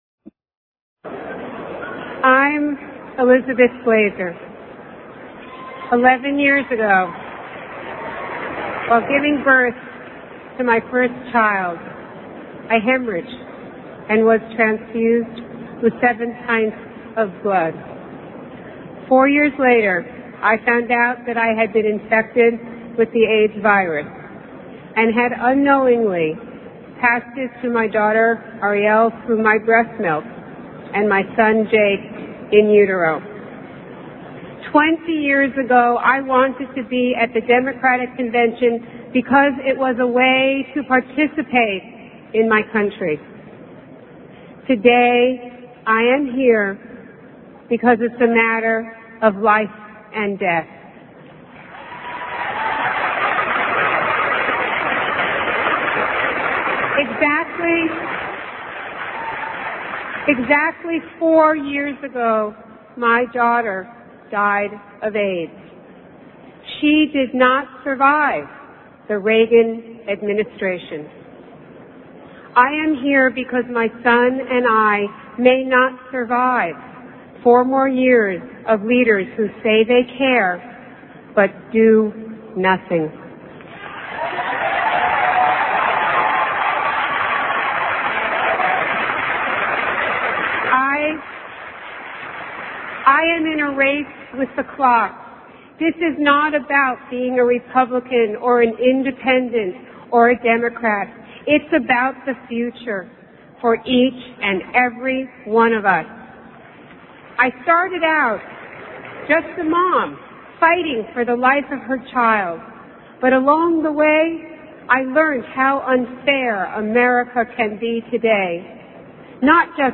1992 Democratic National Convention Address